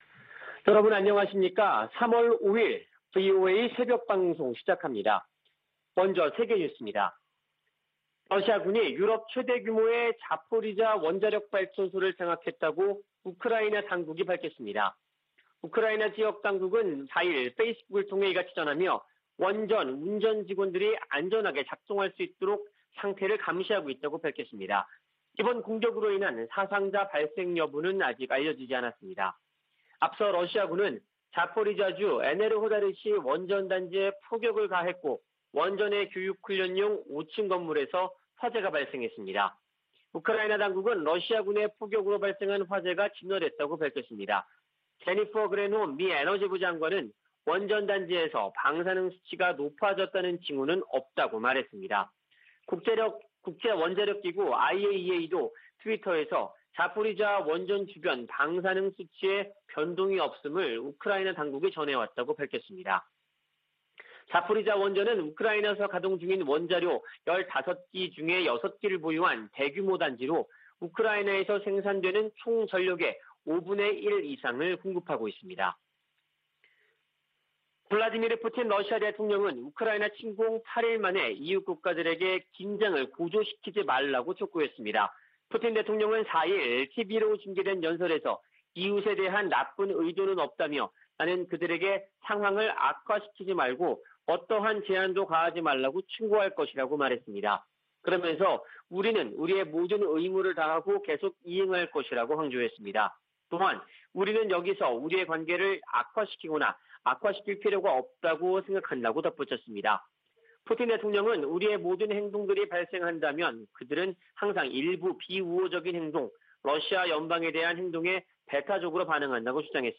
VOA 한국어 '출발 뉴스 쇼', 2022년 3월 5일 방송입니다. 미 국무부는 러시아가 한국의 제재 동참에 유감을 표명한 데 대해, 국제사회가 무의미한 전쟁을 방어하는데 단결하고 있다고 강조했습니다. 북한이 우크라이나 사태에 러시아를 적극 두둔한 것은 핵 보유국 지위 확보를 노린 것이라는 분석이 나오고 있습니다. 미 상원의원들이 북한 등을 암호화폐 악용 국가로 지목하고 대책 마련을 촉구했습니다.